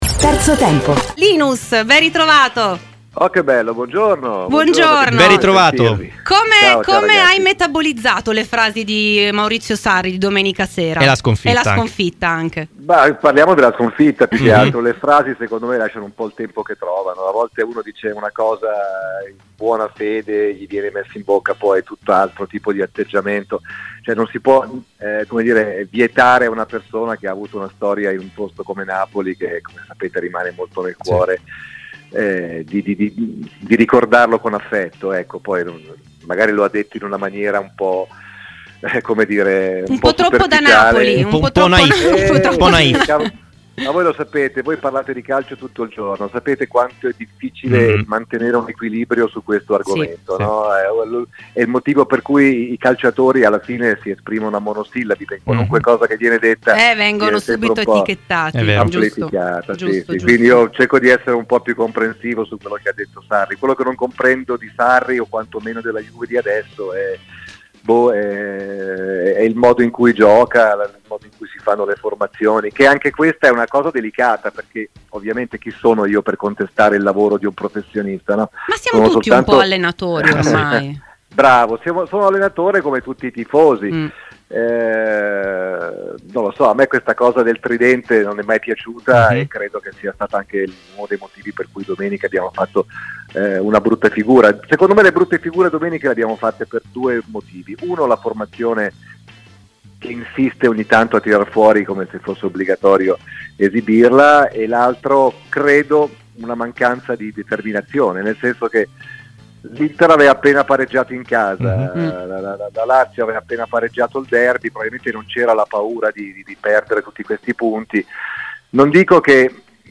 Linus, direttore artistico di Radio Deejay, è intervenuto ai microfoni di Terzo Tempo, su Radio Bianconera.